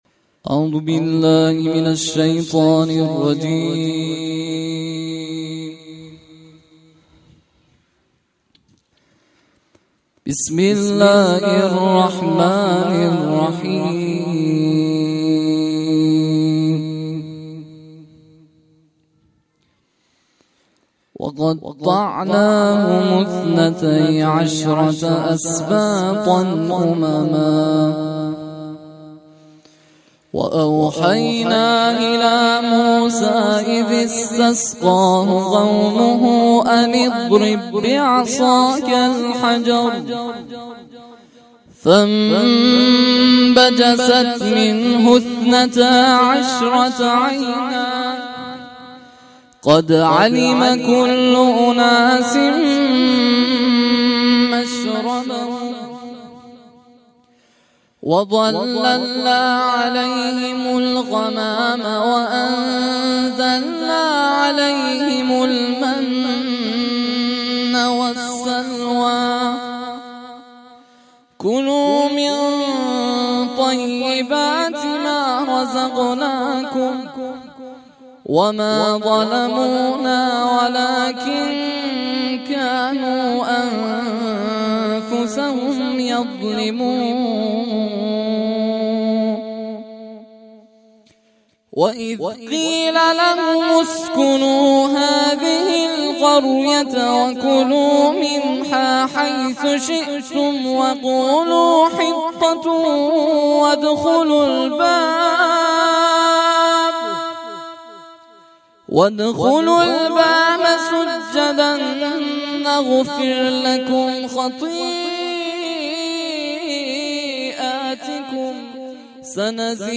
پایگاه اطلاع رسانی ضیافت نور گزارش صوتی یکصد و هفتاد و یکمین کرسی تلاوت و تفسیر قرآن کریم - پایگاه اطلاع رسانی ضیافت نور